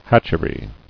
[hatch·er·y]